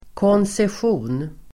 Uttal: [kånsesj'o:n]